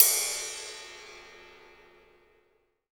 D2 RIDE-09-L.wav